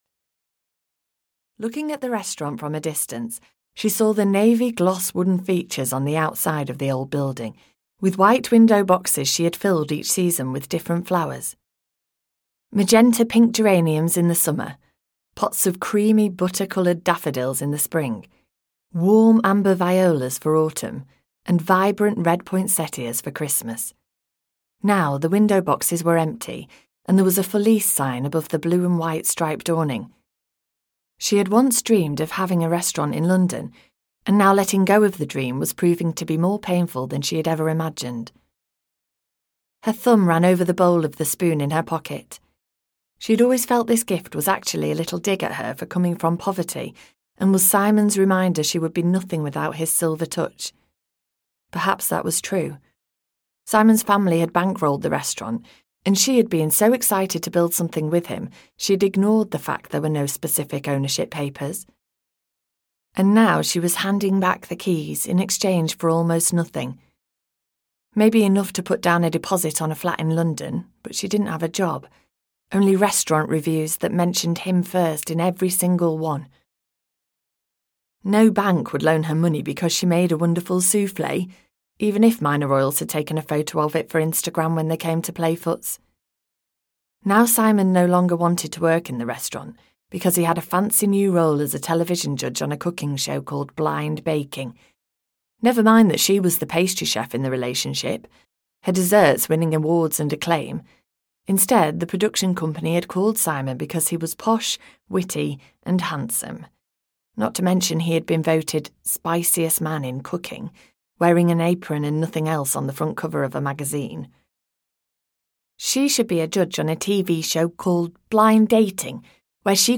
Christmas Wishes at Pudding Hall (EN) audiokniha
Ukázka z knihy